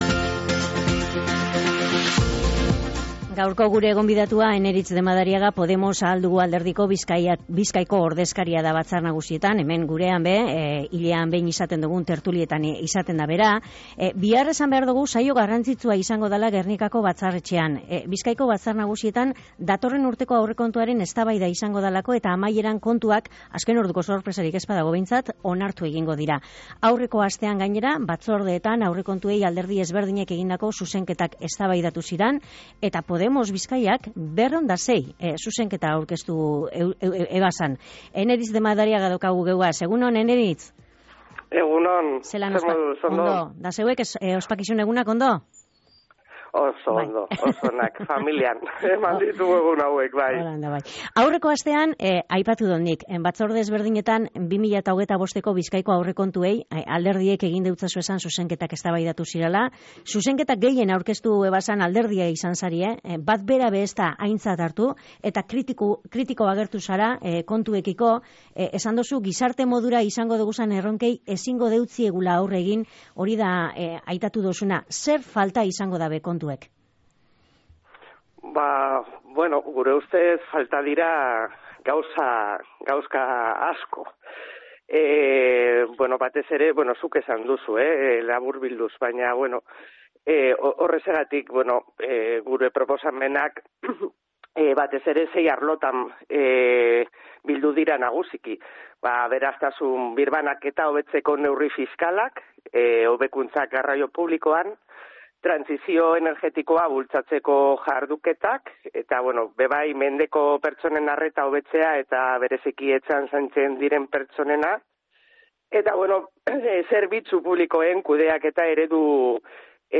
Elkarrekin Bizkaia taldeko batzarkideak, Eneritz de Madariagak, Bizkaia Irratian.